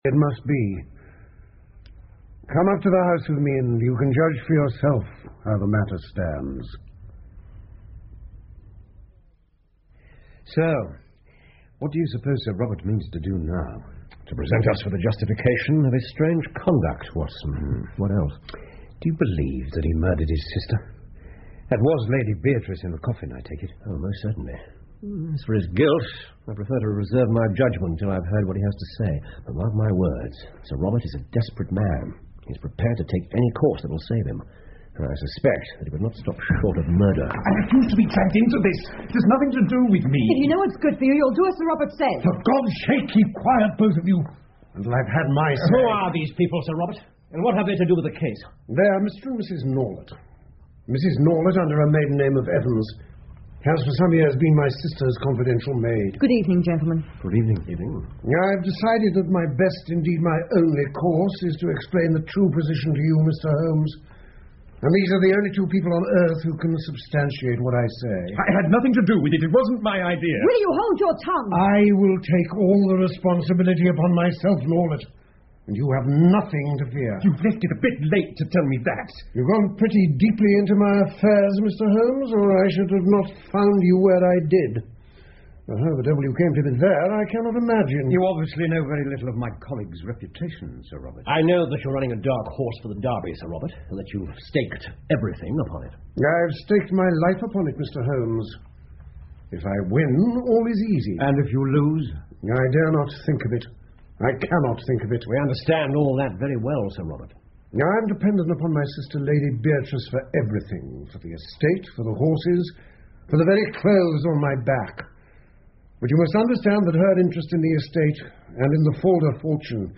福尔摩斯广播剧 Shoscombe Old Place 7 听力文件下载—在线英语听力室